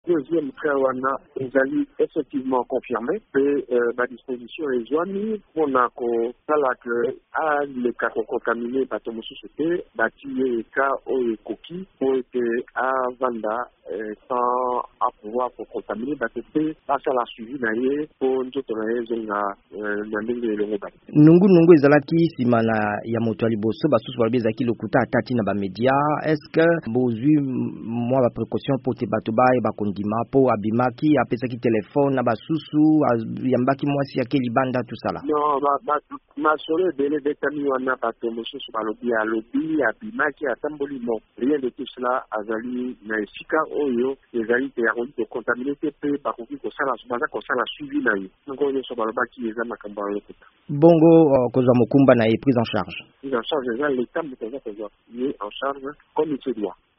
Moto ya mibale azwami na bokono bwa Coronavirus na Kinshasa, engumba mokonzi ya ekolo Congo démocratique. VOA Lingala ebengaki molobeli ya mbulamatari, David-Jolino Makelele.